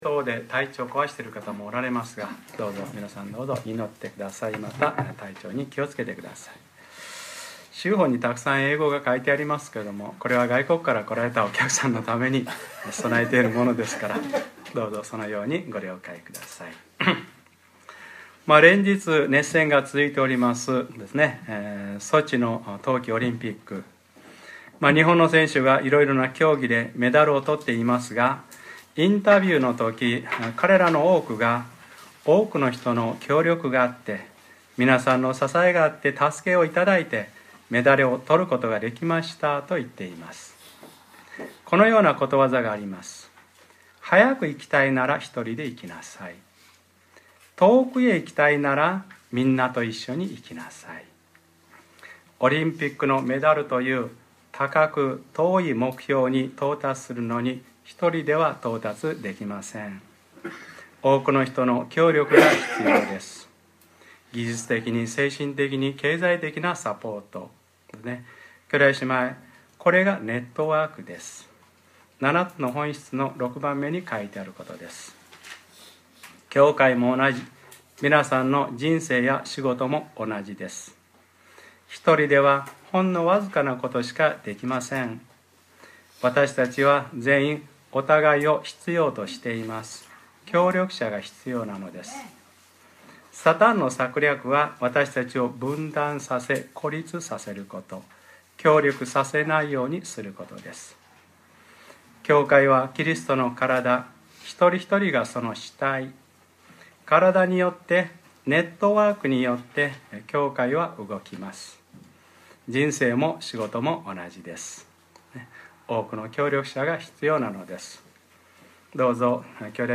2014年2月23日（日）礼拝説教 『黙示録ｰ２７：倒れた 大バビロンが倒れた』 | クライストチャーチ久留米教会